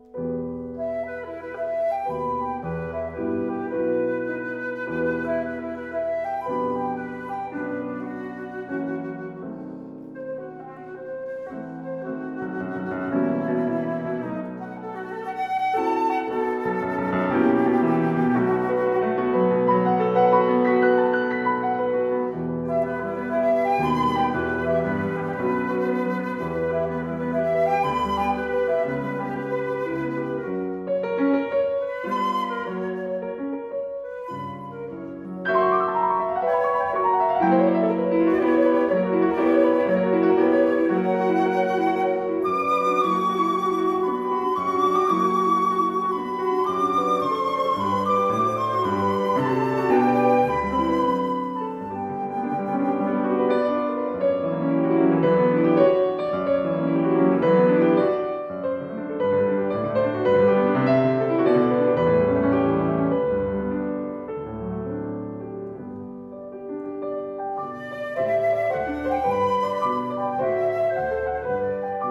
絕妙的音色變化與平衡完美的氣息運動